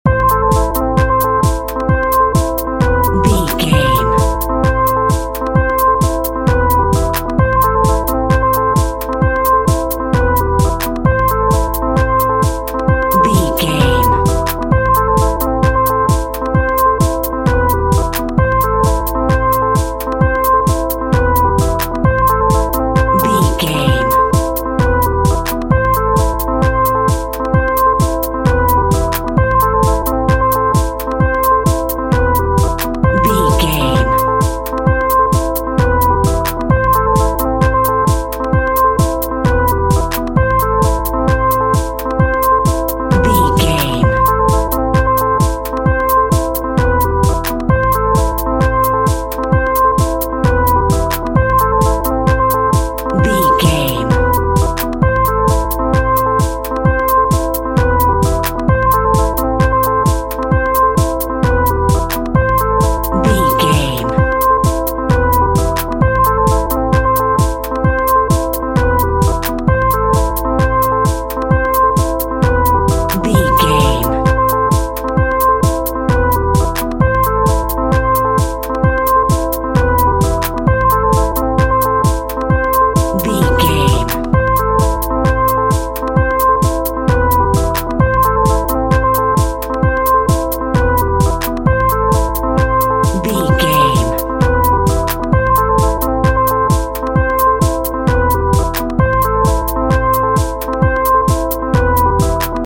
House Music for a Cold Night.
Aeolian/Minor
Fast
energetic
hypnotic
industrial
melancholy
drum machine
synthesiser
electro house
synth lead
synth bass